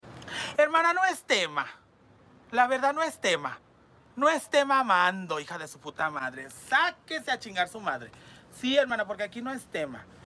no es tema Meme Sound Effect